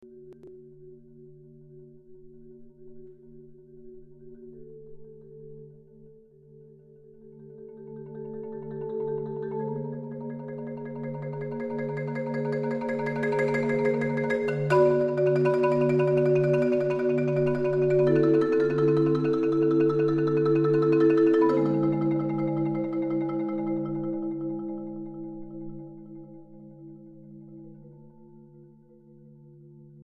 for marimba and flute